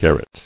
[gar·ret]